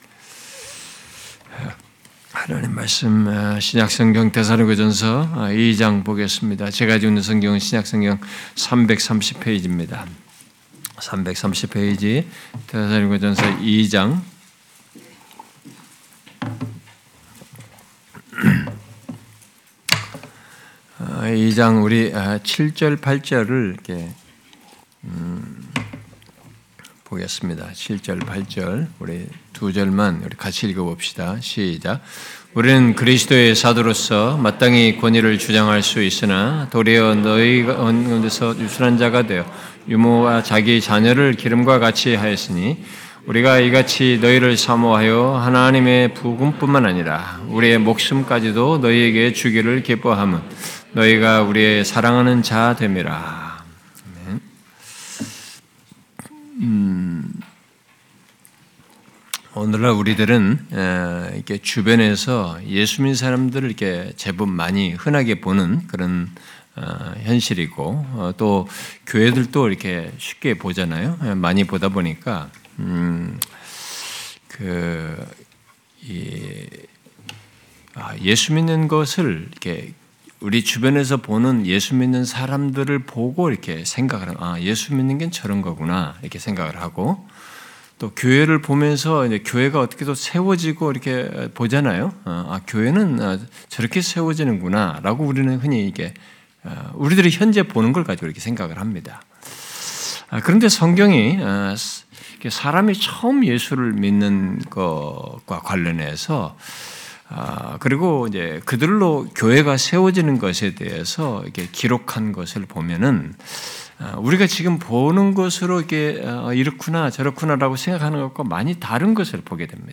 하늘영광교회 주일 설교